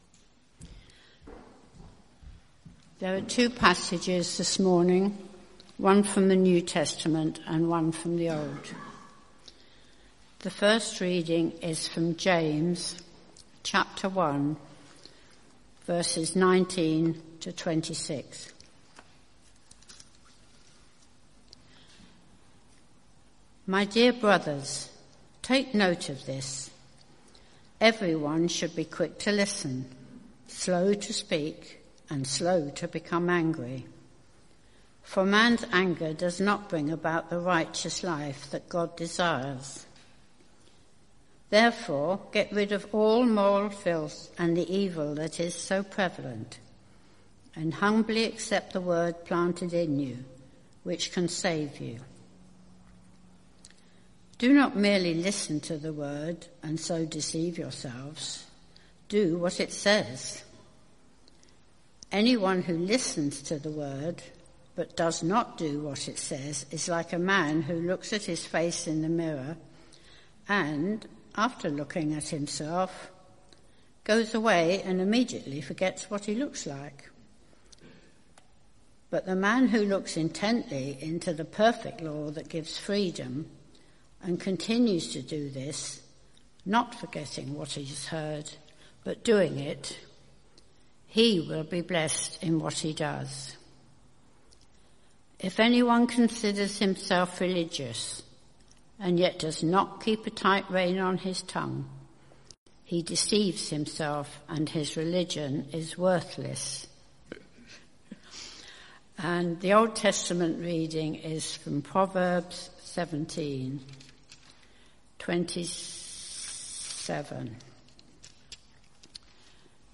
Sermon Series: Taming the Tongue